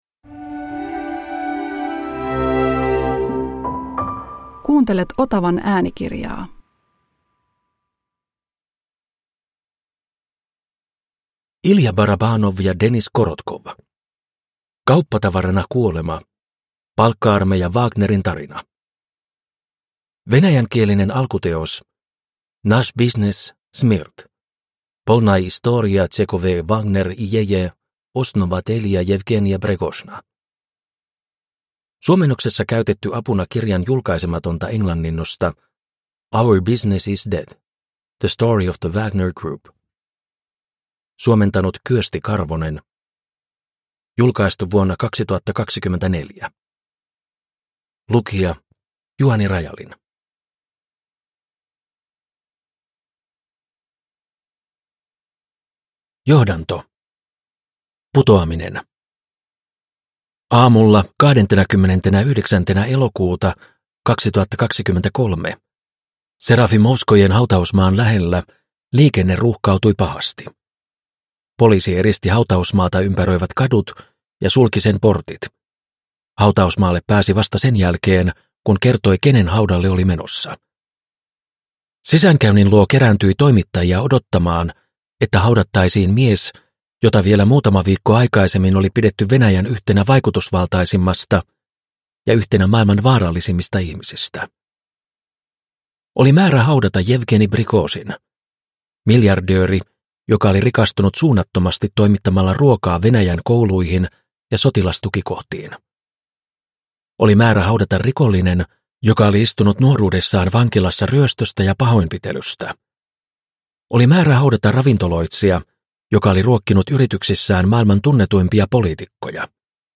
Kauppatavarana kuolema – Ljudbok